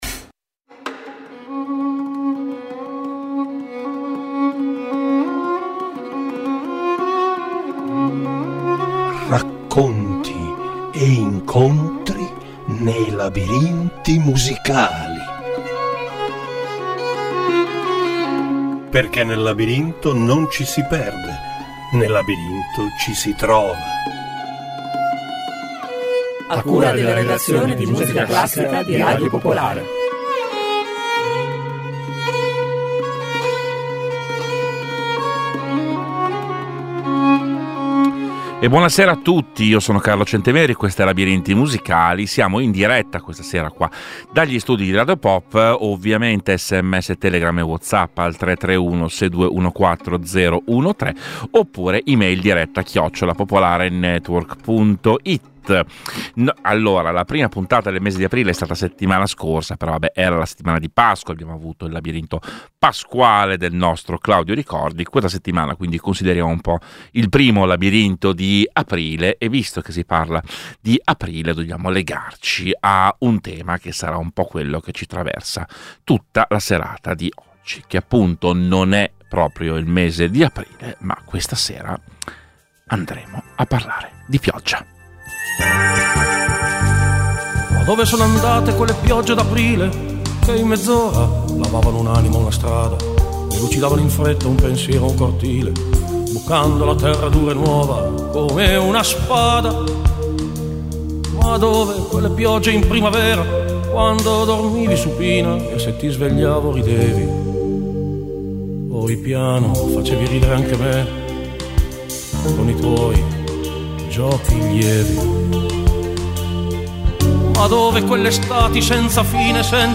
"Labirinti Musicali" ideato dalla redazione musicale classica di Radio Popolare, in ogni episodio esplora storie, aneddoti e curiosità legate alla musica attraverso racconti che intrecciano parole e ascolti.